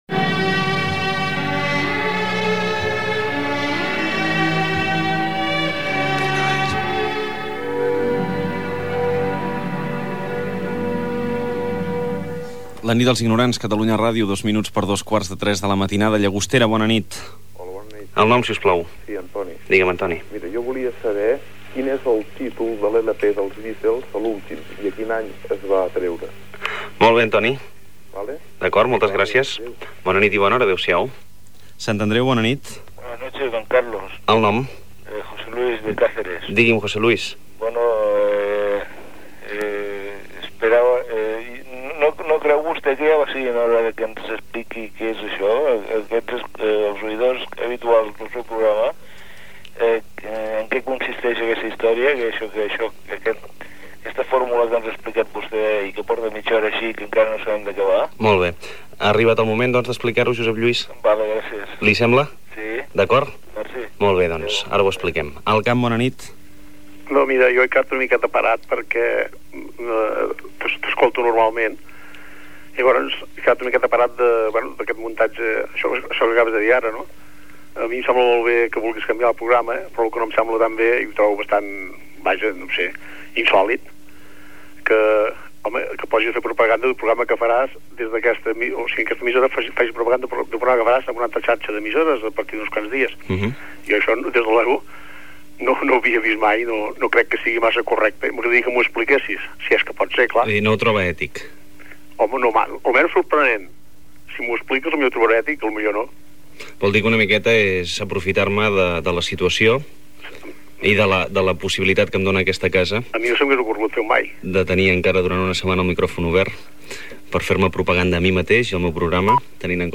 Trucades ficcionades dels oïdors fent preguntes en una edició especial en la que es va fer una adaptació de l'obra de teatre "Trucades a mitjanit", que es representava a Barcelona.
FM